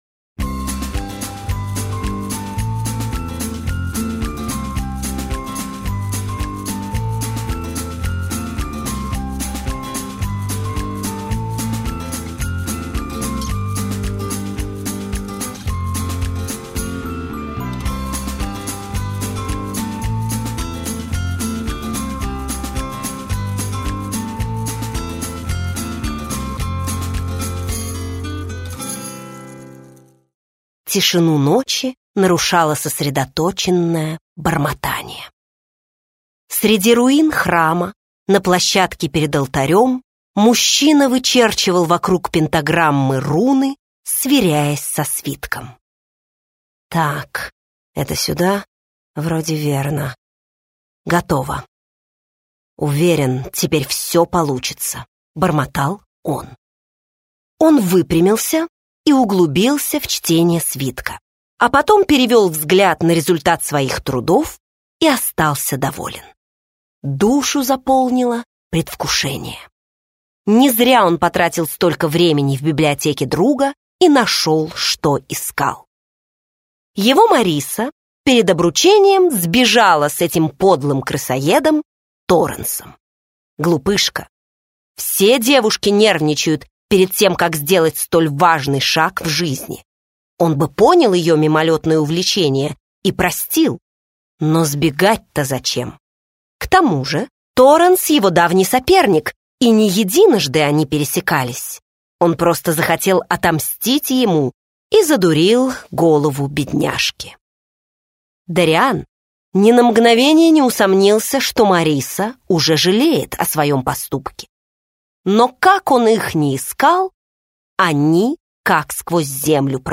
Аудиокнига Проделки богини, или Невесту заказывали? - купить, скачать и слушать онлайн | КнигоПоиск